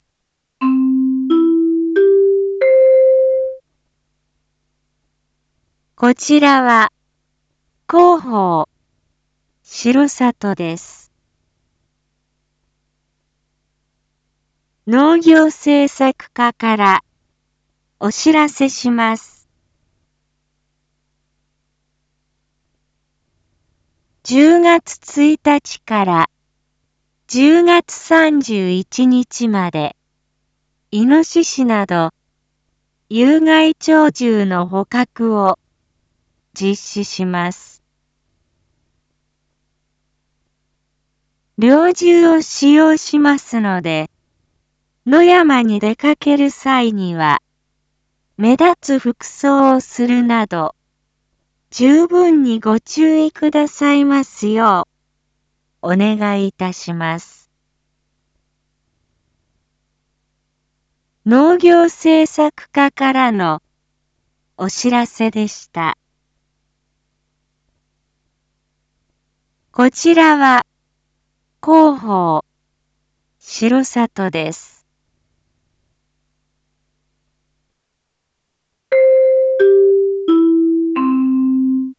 一般放送情報
Back Home 一般放送情報 音声放送 再生 一般放送情報 登録日時：2024-10-20 07:01:23 タイトル：⑬有害鳥獣捕獲について インフォメーション：こちらは、広報しろさとです。